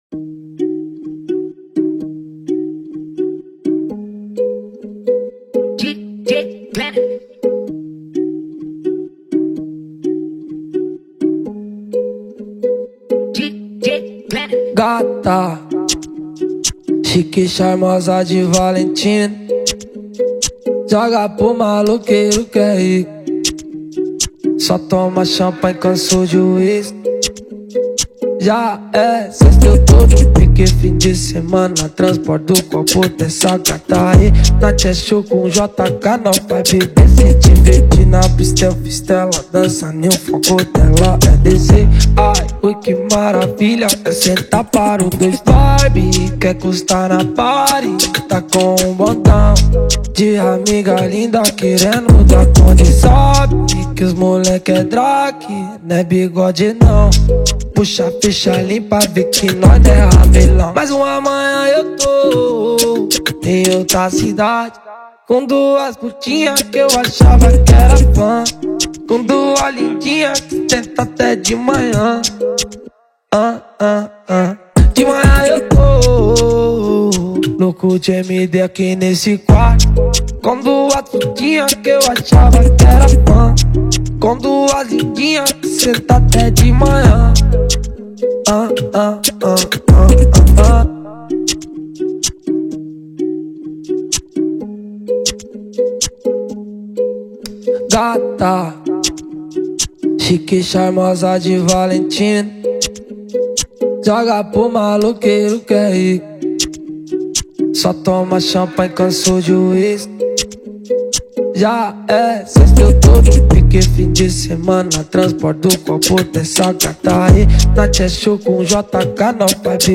2024-11-06 23:25:04 Gênero: MPB Views